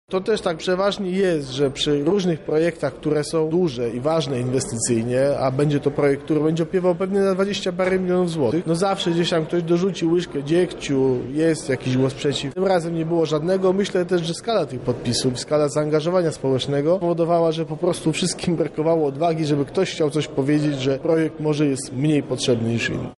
Nie było żadnego głosu przeciw – mówi Piotr Kowalczyk, Przewodniczący Rady Miasta Lublin